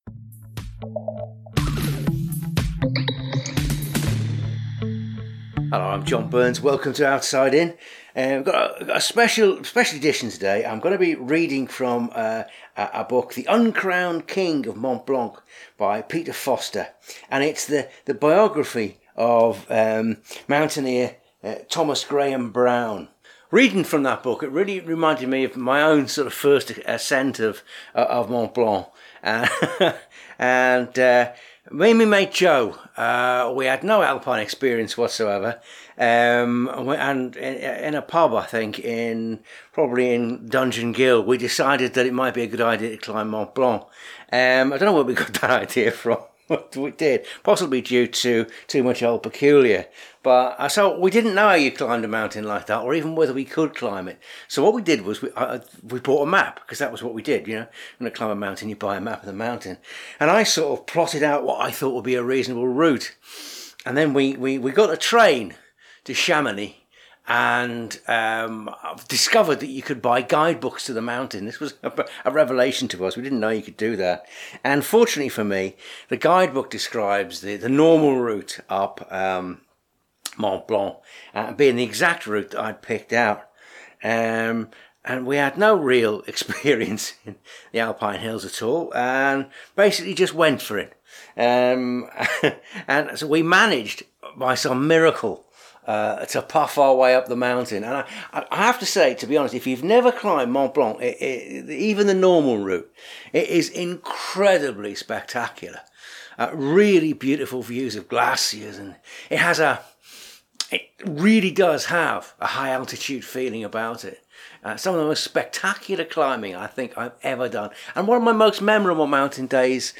In this episode I’ll be sharing my own memories of climbing Mont Blanc and also reading from Peter Foster’s book about T. Graham Brown The Uncrowned King of Mont Blanc. Brown was a pioneering climber in the range between the wars.